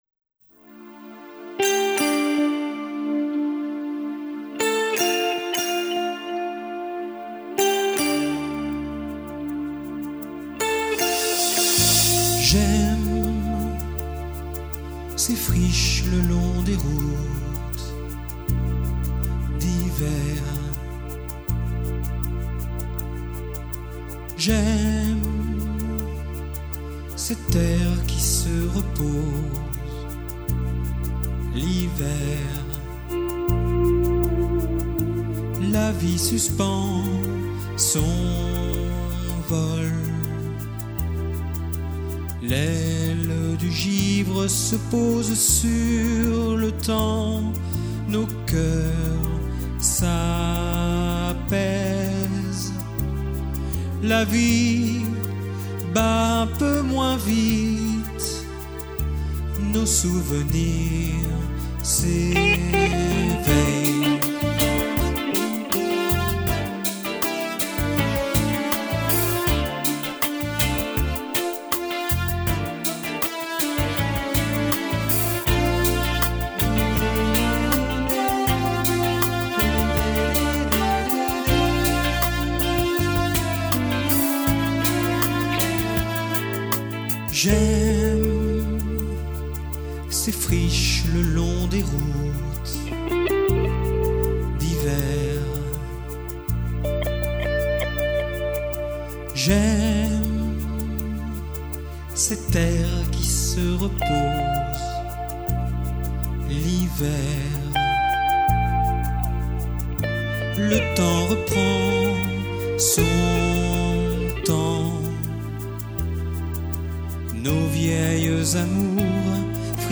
Télécharger la maquette MP3